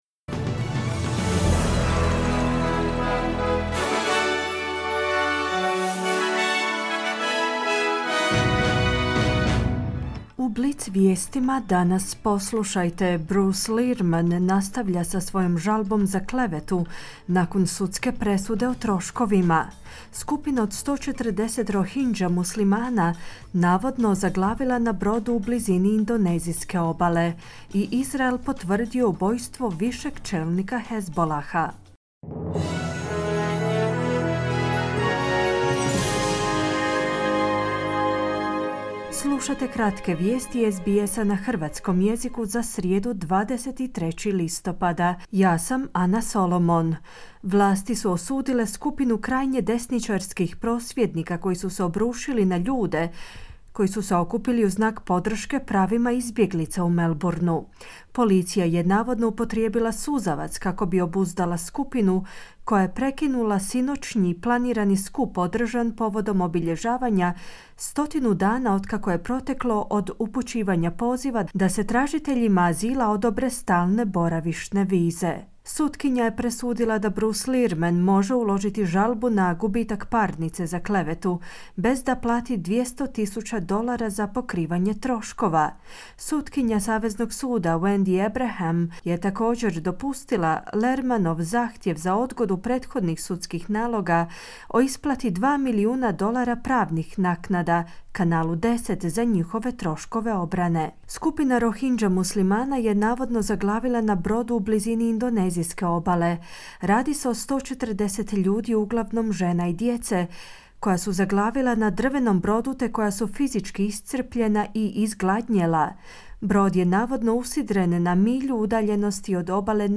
Kratke vijesti SBS-a na hrvatskom jeziku.
Vijesti radija SBS.